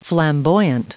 Prononciation du mot flamboyant en anglais (fichier audio)